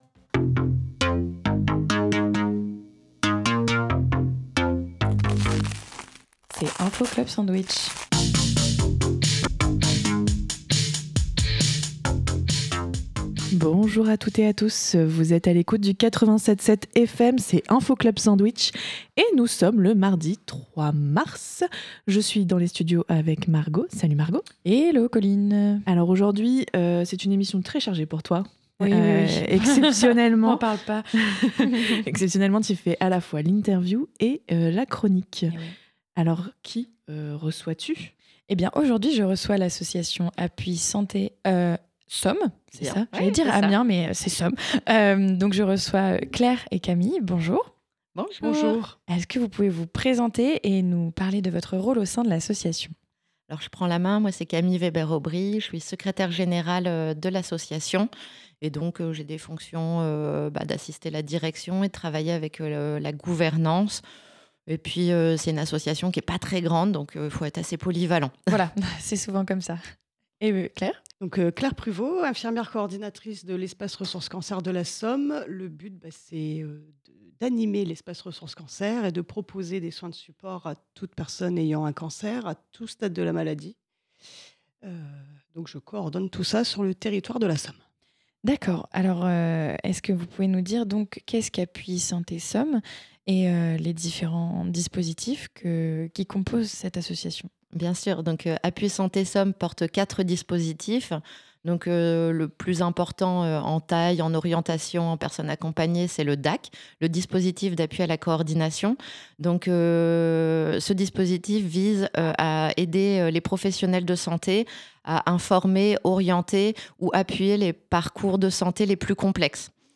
[INFO CLUB SANDWICH] Mardi 03 mars 2026 : Interview avec l'asso Appui Santé Somme - Radio Campus Amiens - 87.7 FM